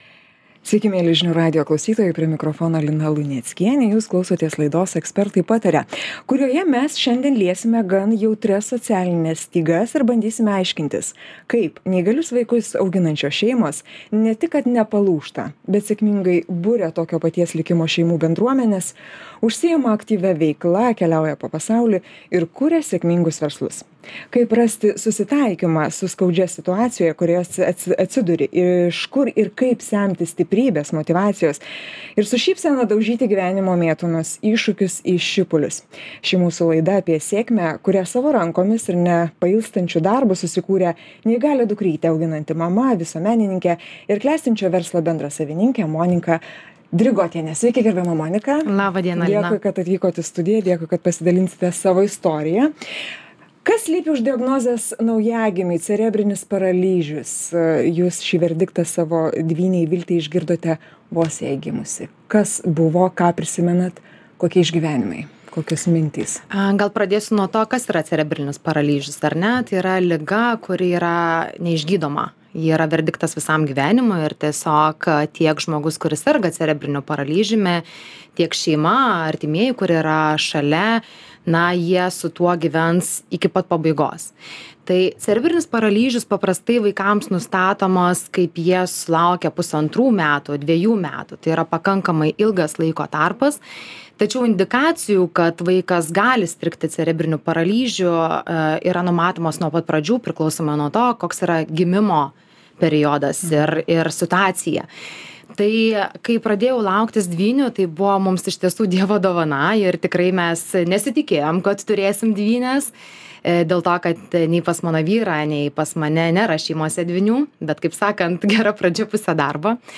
Pokalbis su neįgalų vaiką auginančia mama